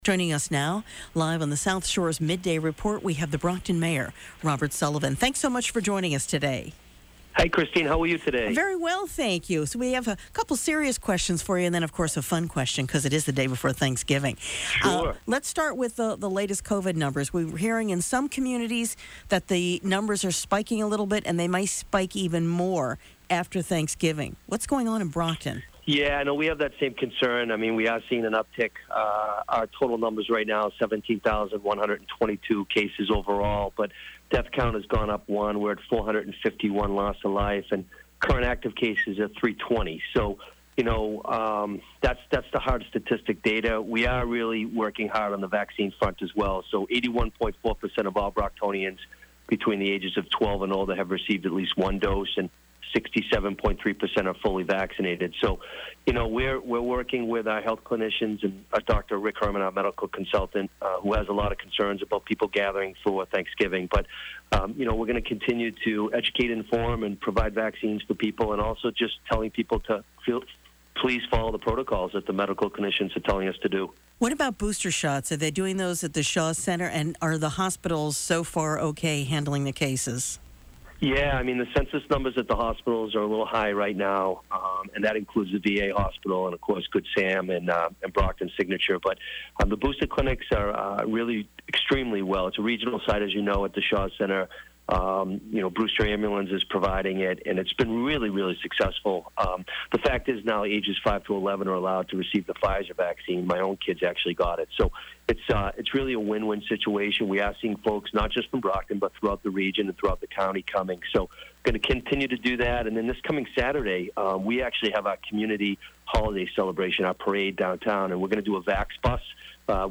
The Brockton Community Holiday Celebration will return this weekend, and Mayor Robert Sullivan joined WATD to discuss the event.